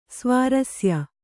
♪ svārasya